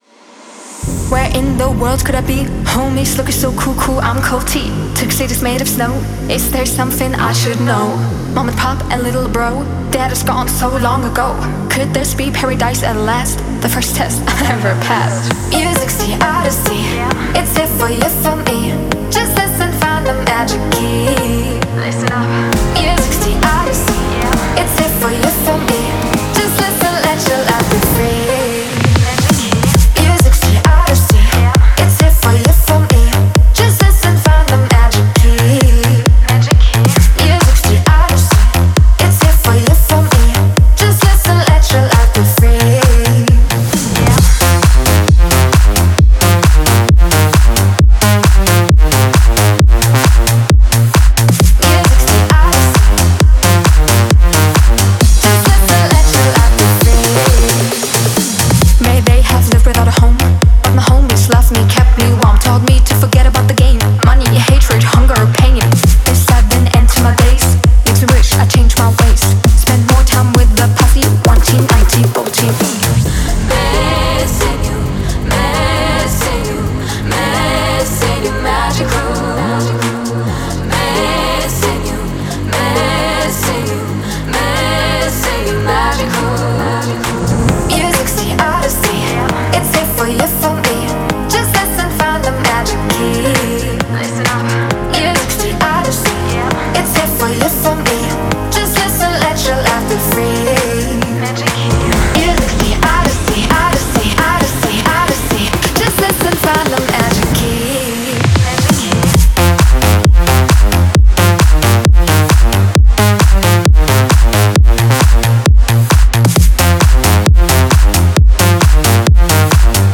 это энергичная трек в жанре EDM
Эмоциональный вокал